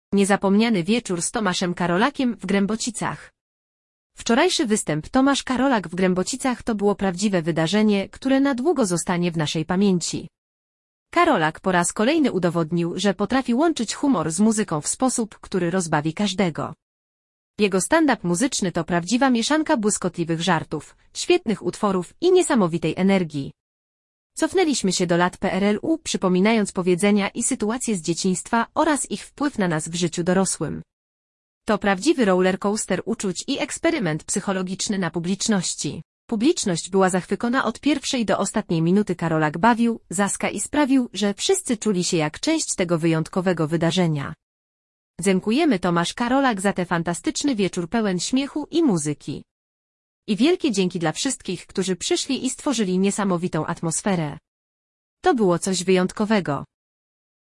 Niezapomniany wieczór z Tomaszem Karolakiem w Grębocicach!
Jego stand-up muzyczny to prawdziwa mieszanka błyskotliwych żartów, świetnych utworów i niesamowitej energii! Cofnęliśmy się do lat PRL-u, przypominając powiedzenia i sytuacje z dzieciństwa oraz ich wpływ na Nas w życiu dorosłym!